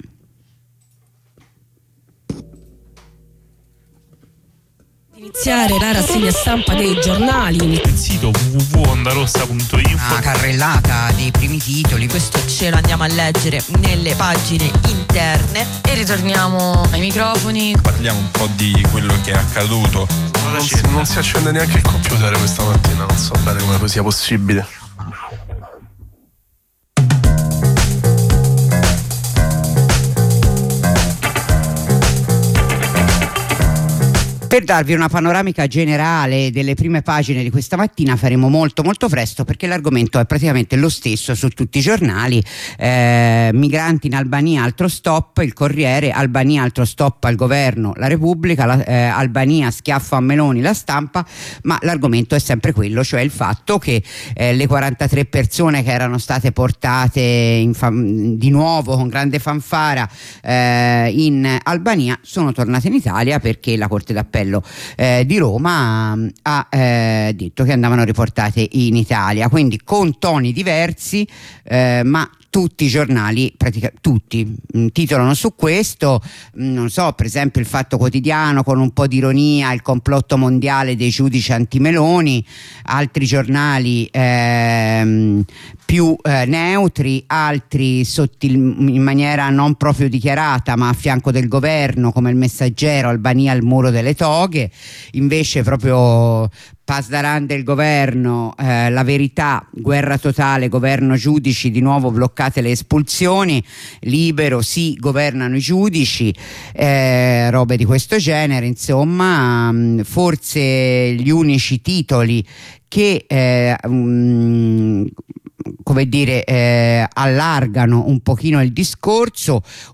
Rassegna stampa - Sabato 1 Febbraio 2025
Lettura delle prime pagine e pagine interne dei quotidiani italiani e locali della città di Roma.